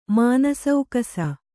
♪ mānasaukasa